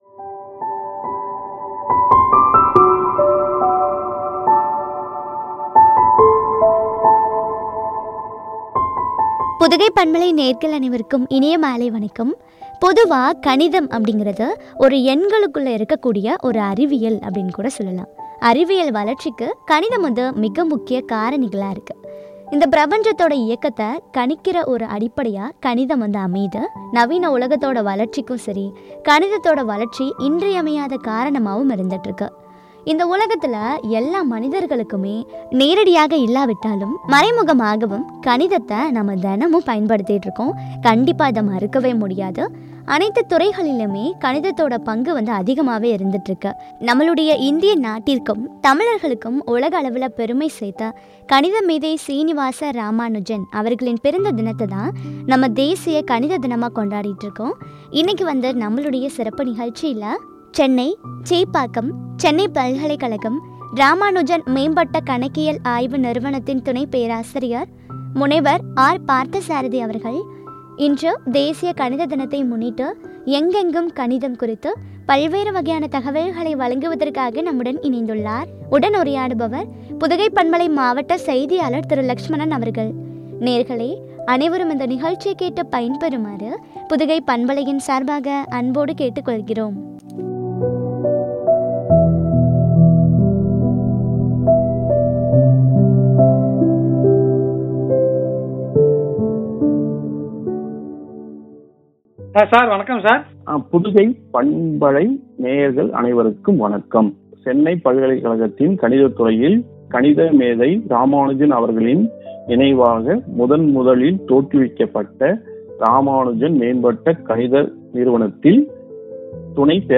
“எங்கெங்கும் கணிதம்” குறித்து வழங்கிய உரையாடல்.